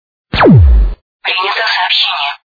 Файл принято сообщение.mp3
на входящий СМС